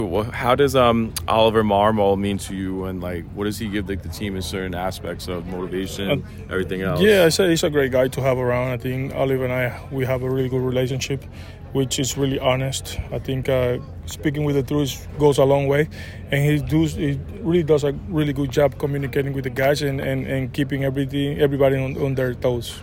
Contreras spoke before the Cardinals’ spring training game against the New York Mets at Roger Dean Chevrolet Stadium.
Wilson-Contreras-Interview-on-Manager-Oli-Marmol_mixdown.mp3